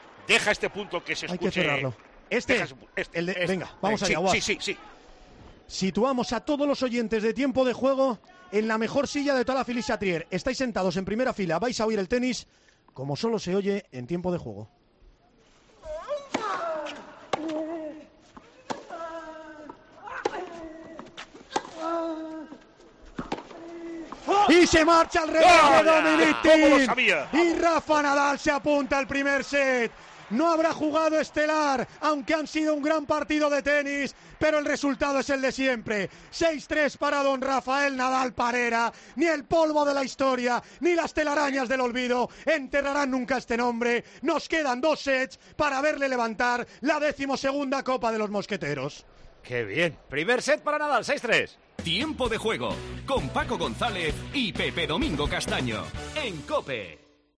COPE, desde la cabina de la pista central de Roland Garros, es testigo de la final entre Rafa Nadal y Dominic Thiem.